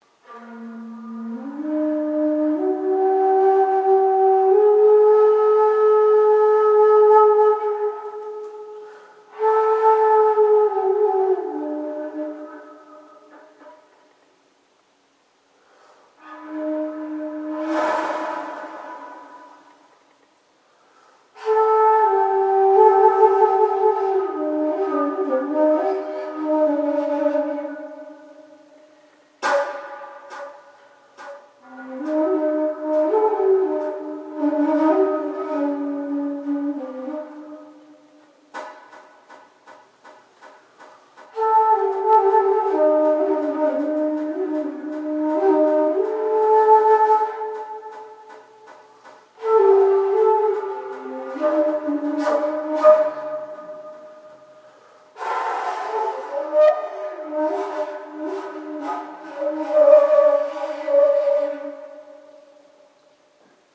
我把所有东西都搬出了我公寓的一个房间......关闭了所有的门。地板是硬木的。这段录音除了在房间里自然发生的混响外，还使用了混响处理，并在这里和那里使用延迟进行了战略性的回声，以达到节奏的效果（从原始干录音中收集的三个片段之一）。